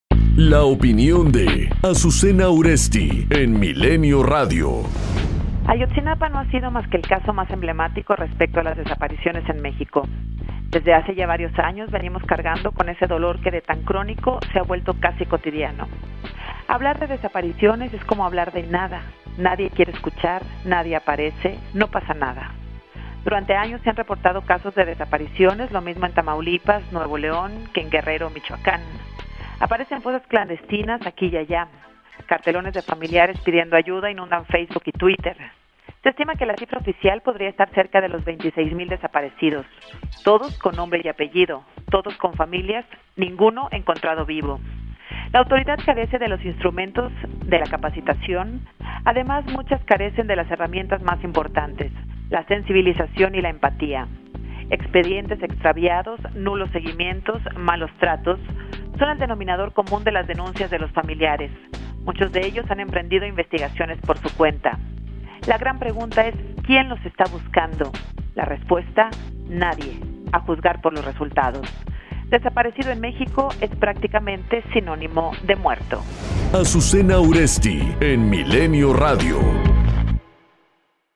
Comentario Azucena Uresti (090915)